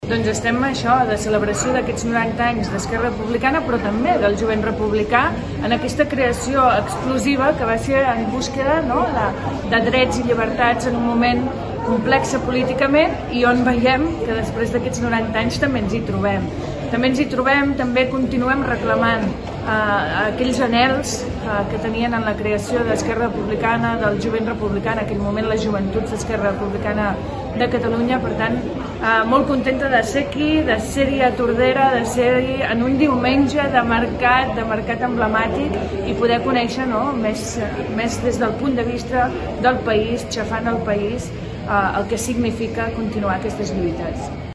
Es commemora el 90è aniversari de Jovent Republicà en un acte a la plaça 1 d’octubre